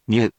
We’re going to show you the character(s), then you you can click the play button to hear QUIZBO™ sound it out for you.
In romaji, 「にゅ」 is transliterated as 「nyu」which sounds sort of like the saying the British English word「new」with the 「ñ」soundーsort of as in 「/njuː/」with no diphthong.